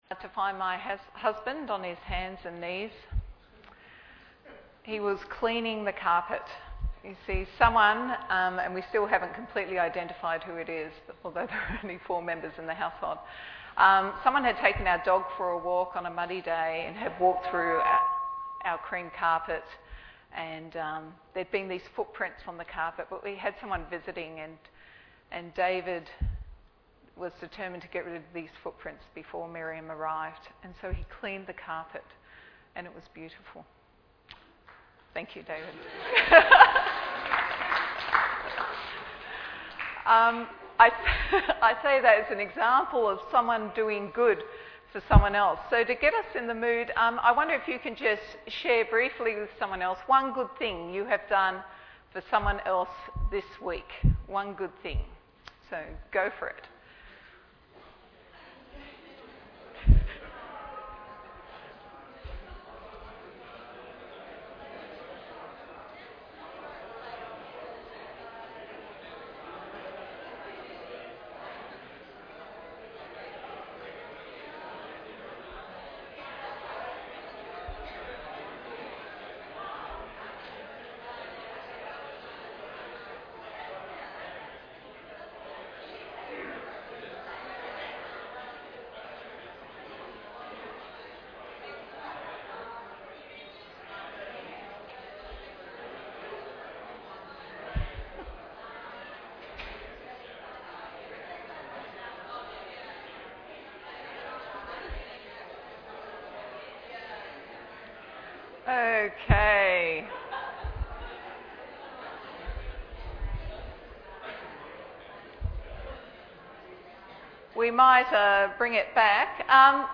Bible Text: Titus 3 | Preacher